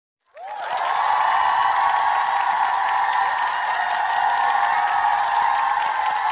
XTRA_happycrowd.mp3